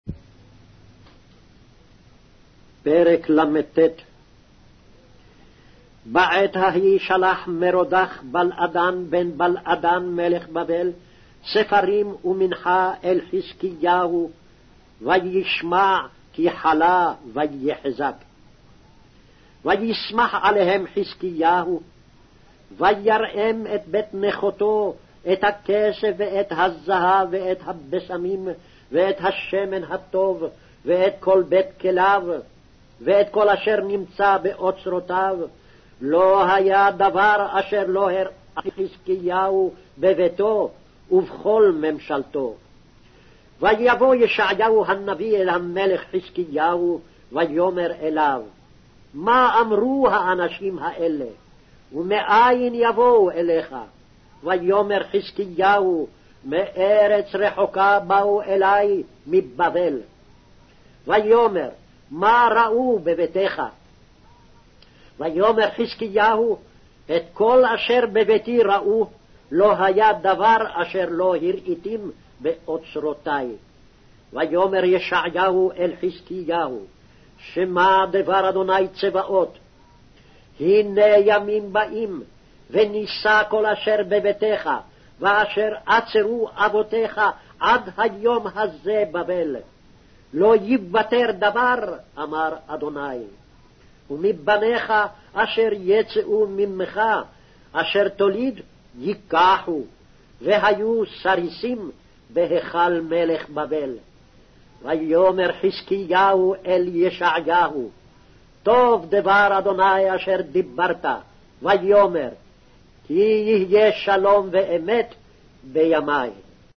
Hebrew Audio Bible - Isaiah 47 in Gnttrp bible version